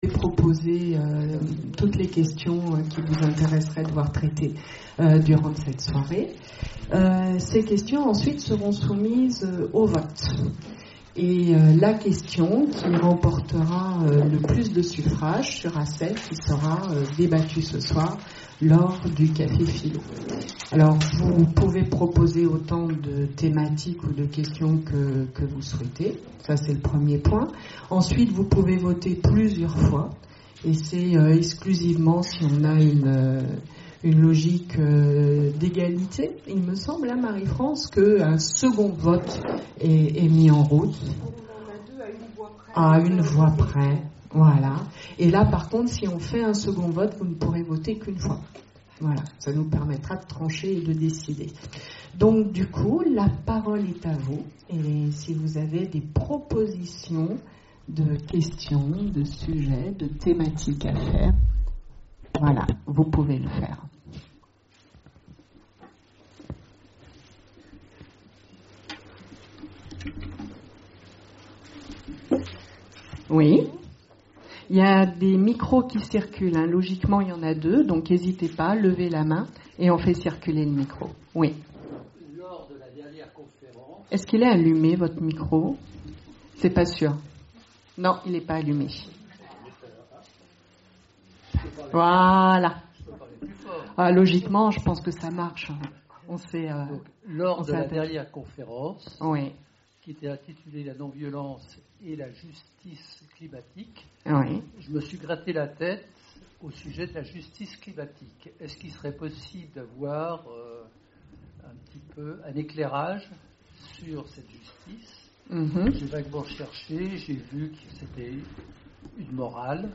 Conférences et cafés-philo, Orléans